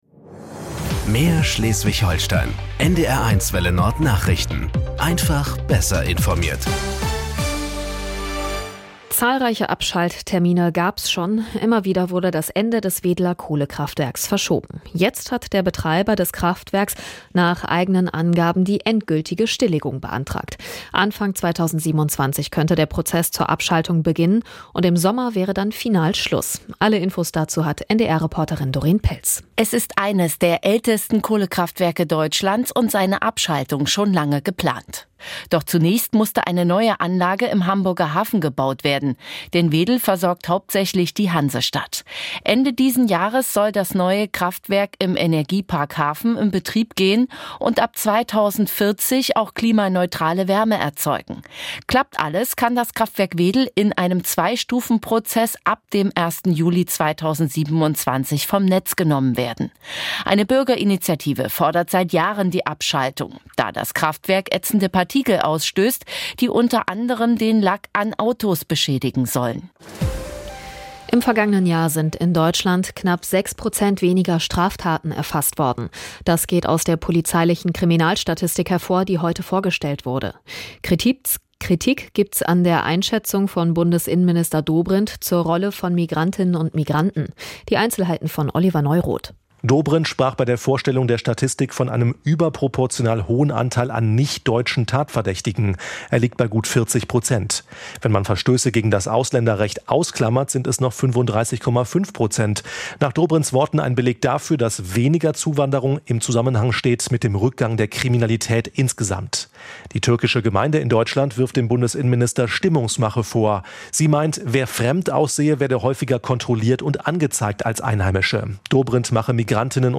Nachrichten 18:00 Uhr - 20.04.2026 ~ NDR 1 Welle Nord – Nachrichten für Schleswig-Holstein Podcast